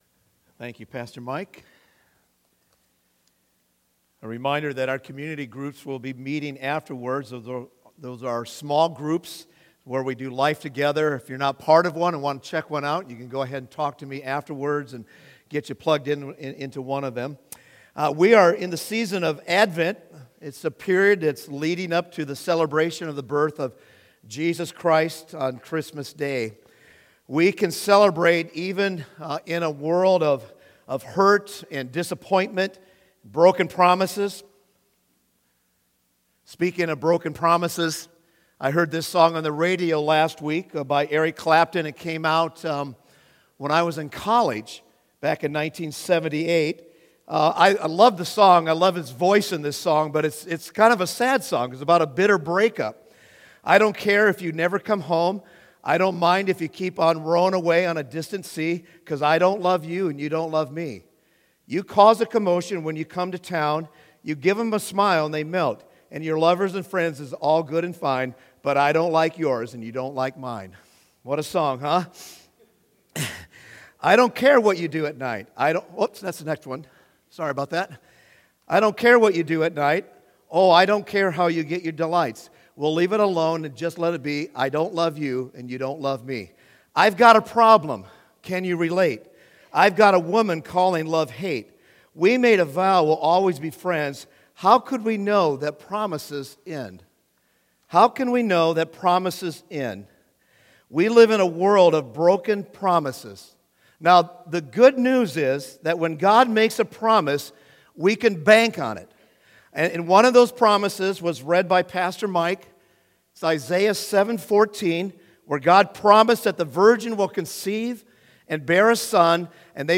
Sermons | Converge Church